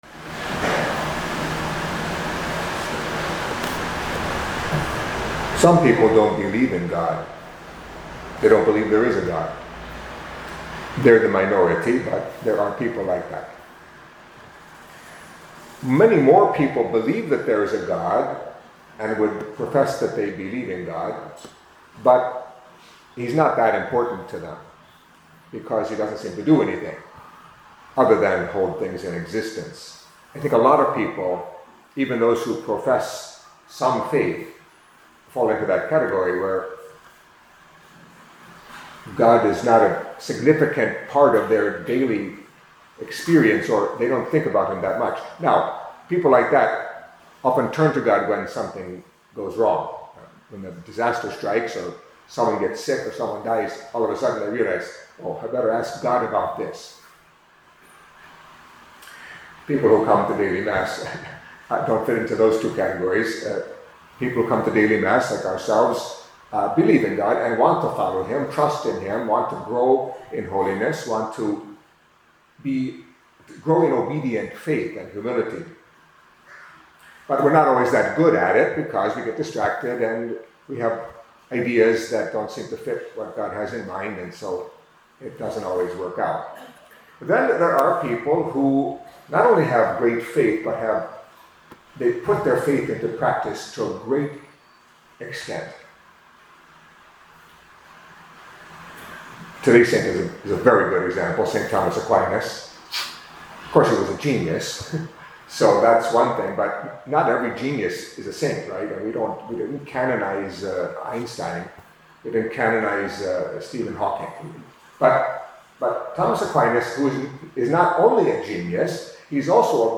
Catholic Mass homily for Wednesday of the Third Week in Ordinary Time